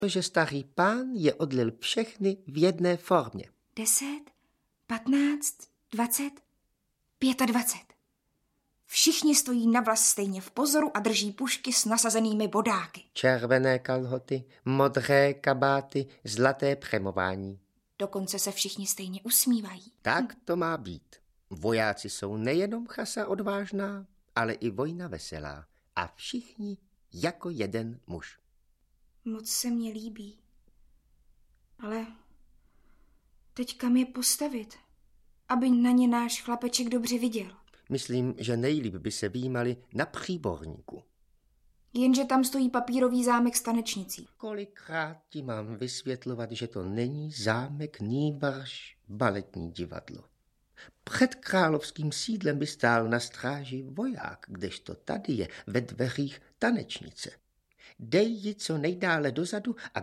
Audiobook
Read: Miroslav Táborský